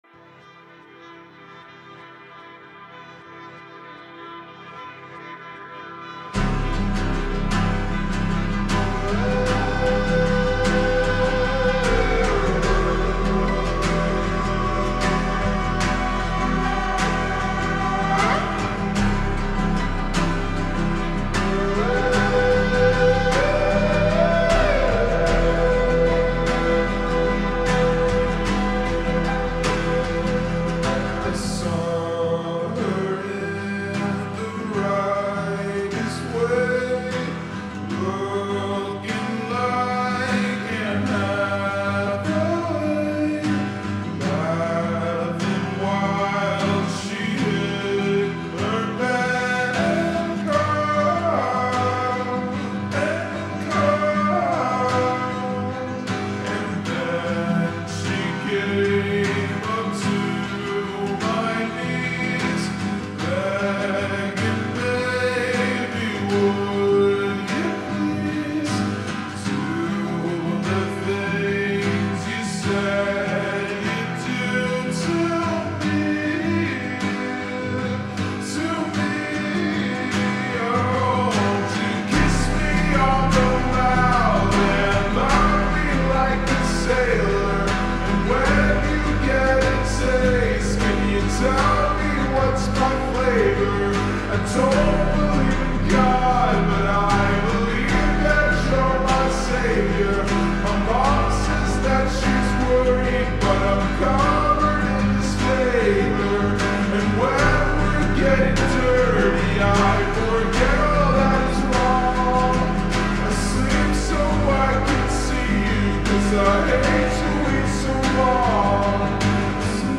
با ریتمی کند شده